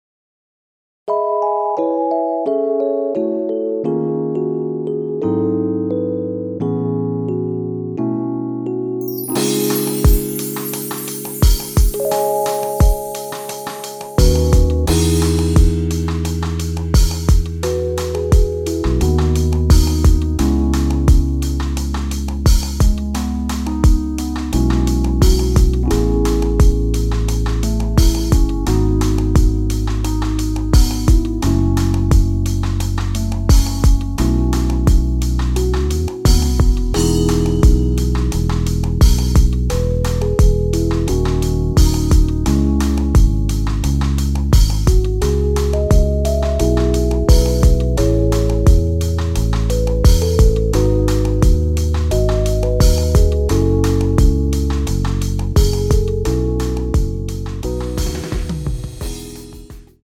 엔딩이 페이드 아웃이라 노래 끝나고 바로 끝나게 엔딩을 만들어 놓았습니다.(원키 멜로디 MR 미리듣기 확인)
앞부분30초, 뒷부분30초씩 편집해서 올려 드리고 있습니다.
중간에 음이 끈어지고 다시 나오는 이유는